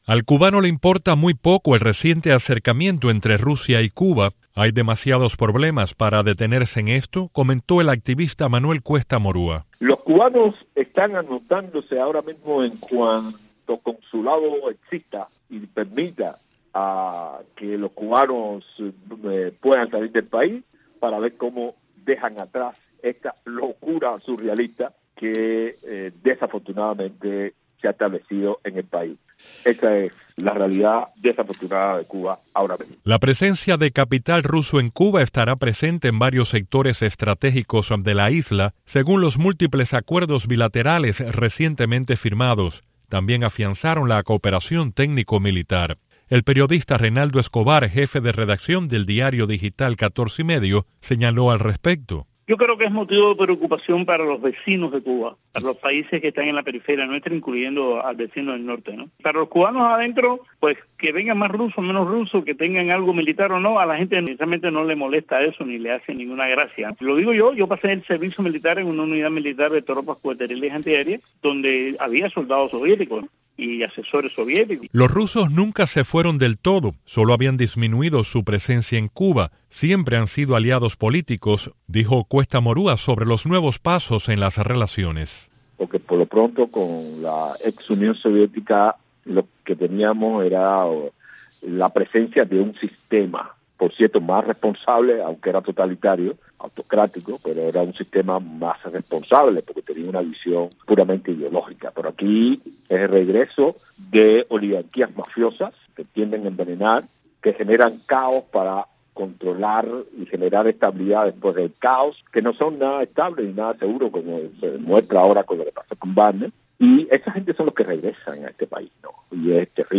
Cubanos hablan del regreso del Kremlin a La Habana